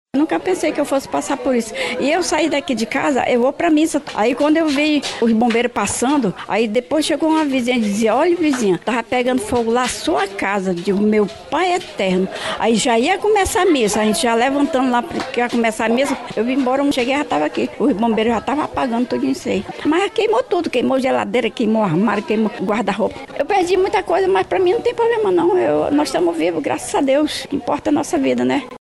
SONORA-MORADORA.mp3